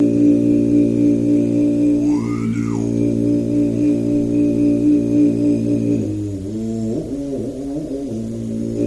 Monk Voice Low Chant